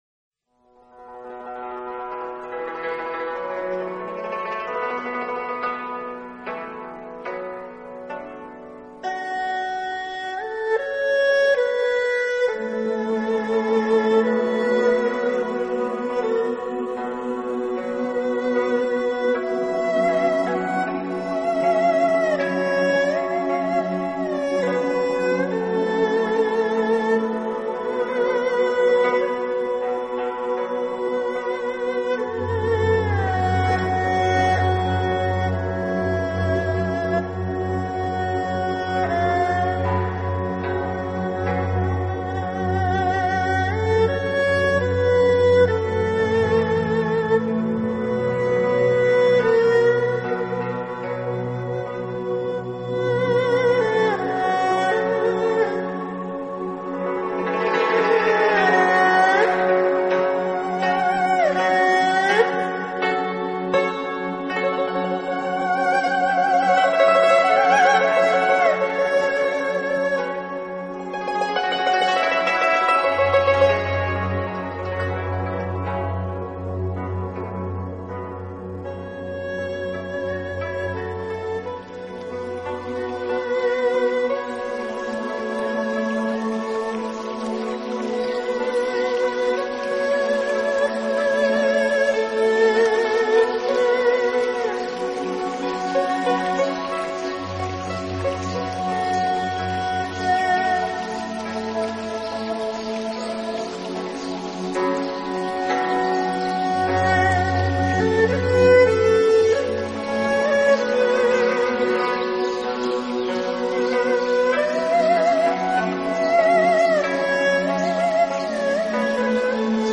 音乐类型：New Age